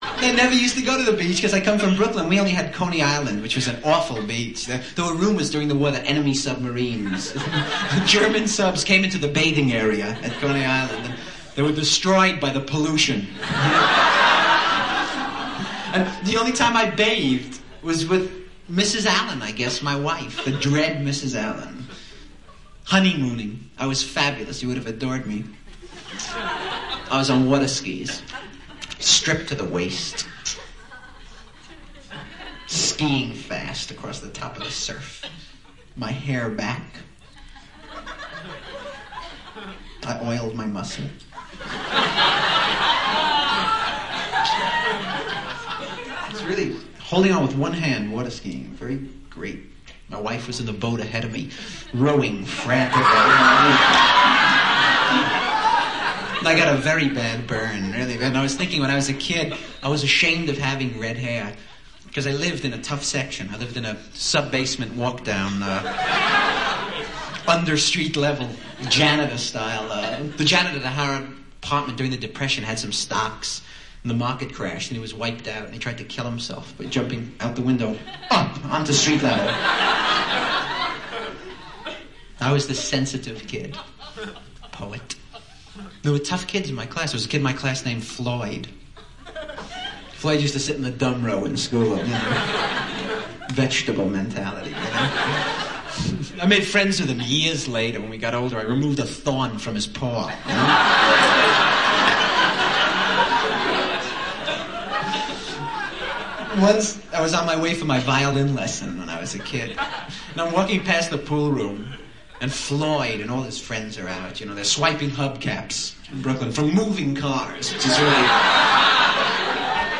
伍迪单口相声精选 第14期:布鲁克林Brooklyn 听力文件下载—在线英语听力室
几乎5秒一个富有哲思的包袱带你体会美国单口相声的不同之处。